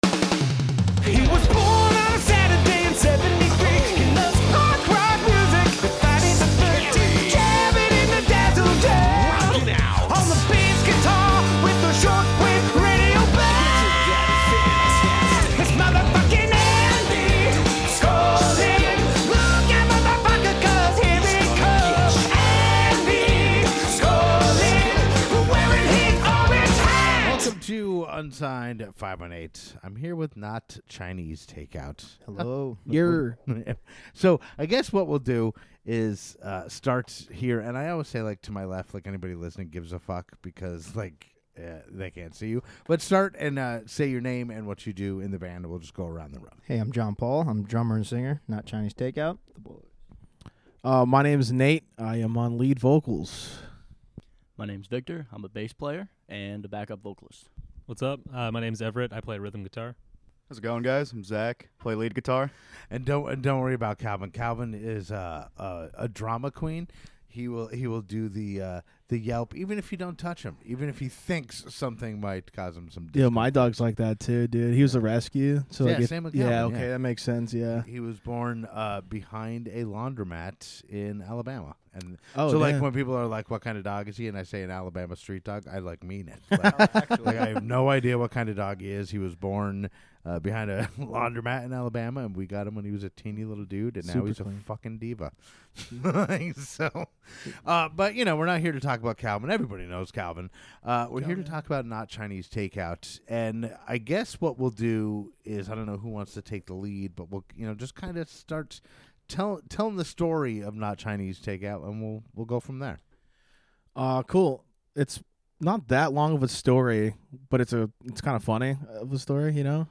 Not Chinese Takeout also perform a song live.